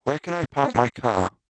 Note that both the LPC-based and PAT-based syntheses use the classic OLA method.
LPC
1_lpc.wav